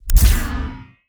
SCIEnrg_Shield Activate_04_SFRMS_SCIWPNS.wav